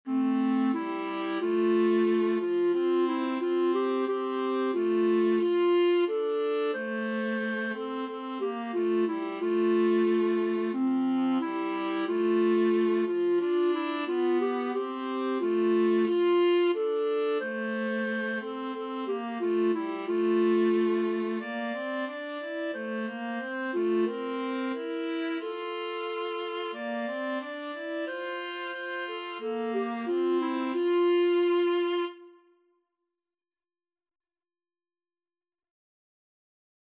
Free Sheet music for Clarinet-Viola Duet
ClarinetViola
Traditional Music of unknown author.
4/4 (View more 4/4 Music)
F major (Sounding Pitch) (View more F major Music for Clarinet-Viola Duet )